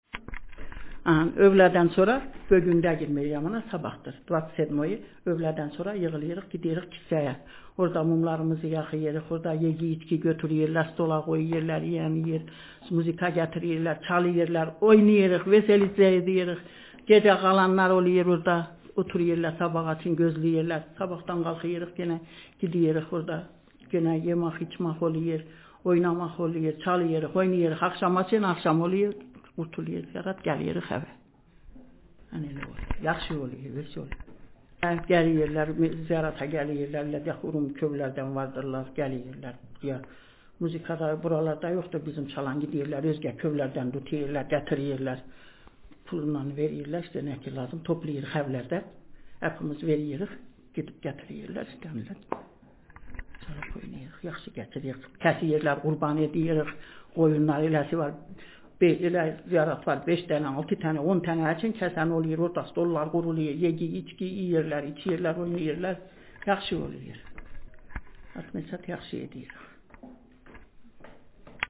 Interlinear glossed text
Speaker sexf
Text genretraditional narrative